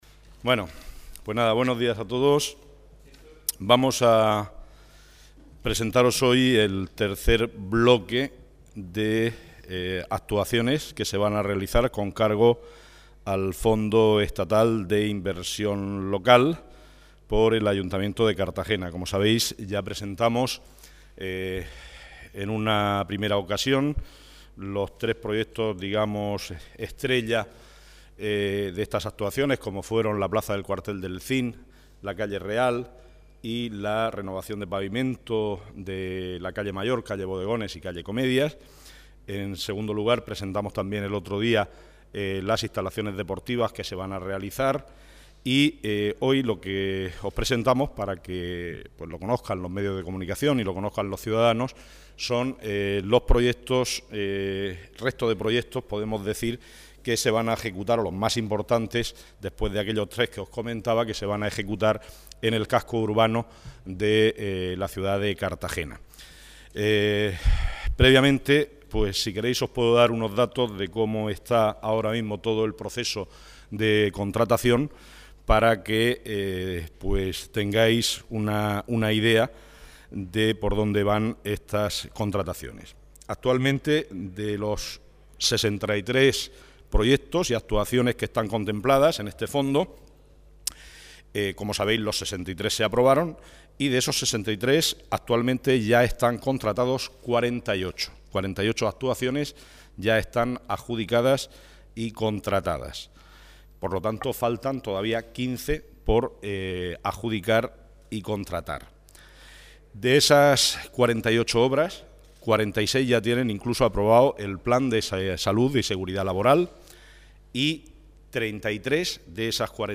Audio: 2009-03-26 Declaraciones de Jose Vicente Albaladejo sobre los proyectos del Plan E (MP3 - 15,62 MB)